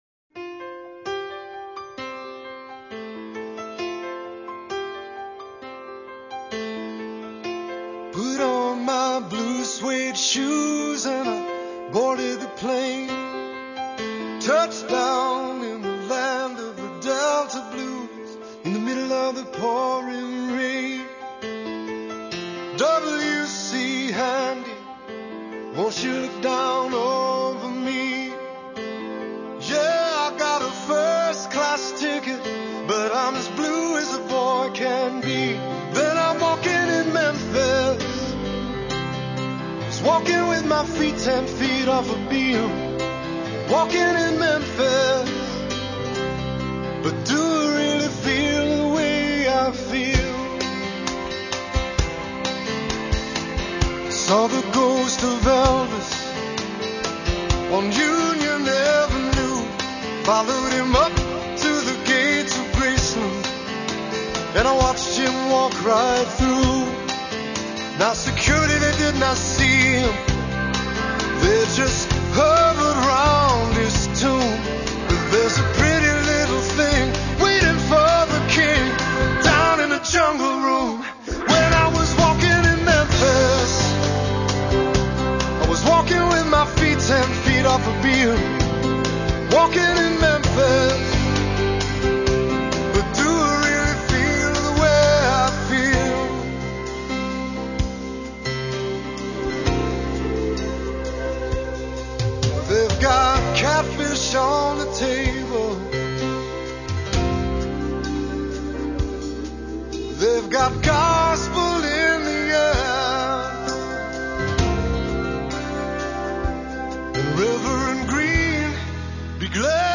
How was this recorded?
22kHz Mono